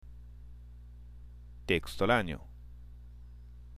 （テ　エクストラーニョ）